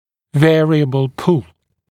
[‘veərɪəbl pul][‘вэариэбл пул]разноуровневая тяга